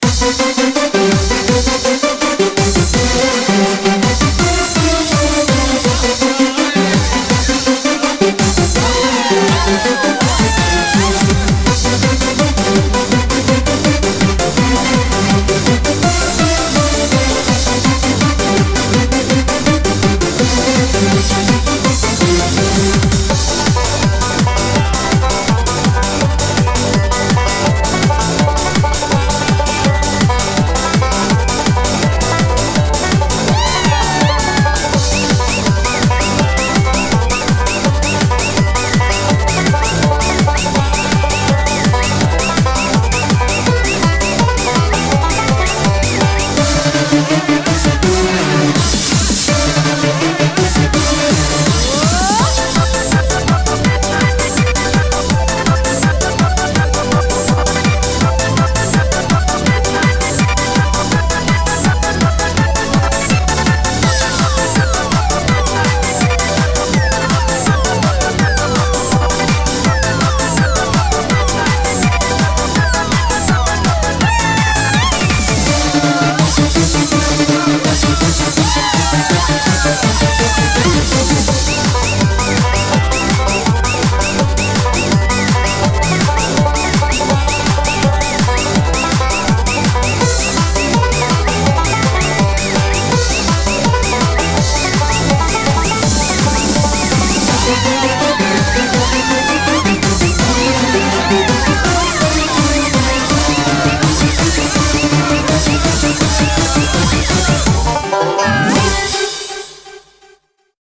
BPM165
Audio QualityMusic Cut